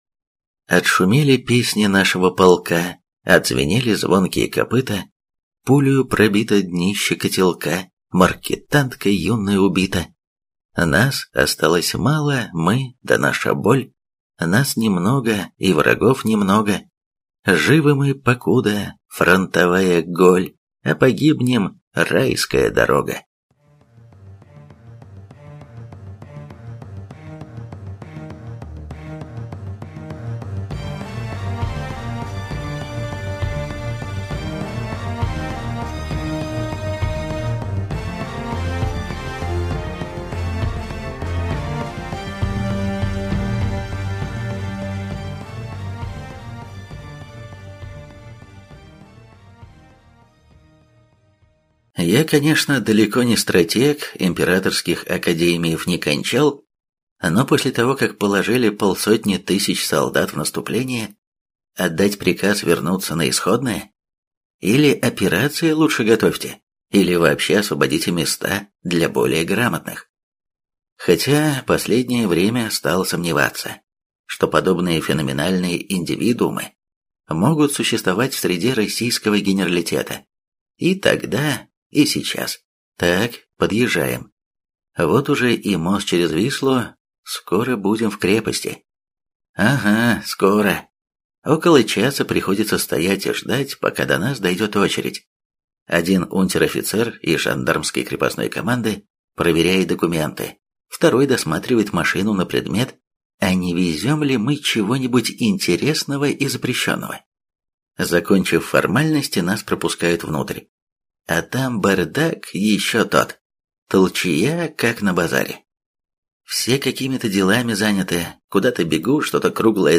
Аудиокнига Большая охота | Библиотека аудиокниг